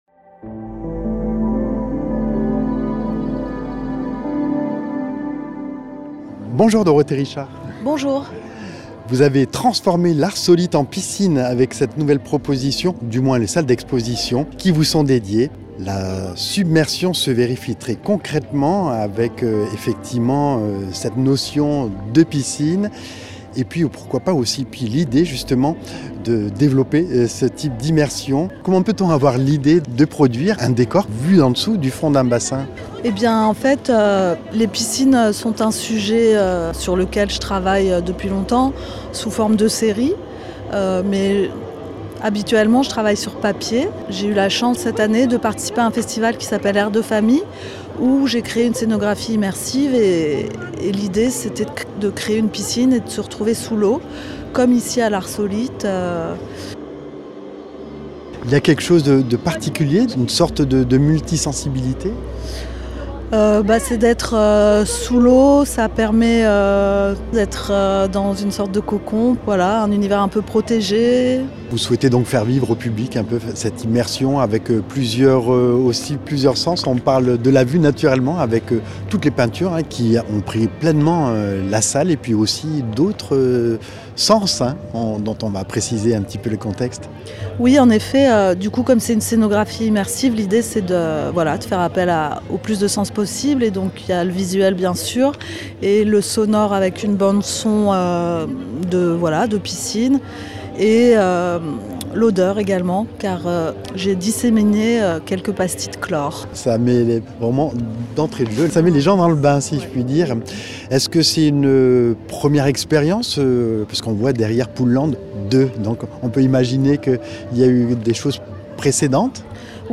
Rencontre avec les artistes lors de la soirée de vernissage au Centre d’art L’Arsolite – St Jean en Royans – le 9 novembre 2024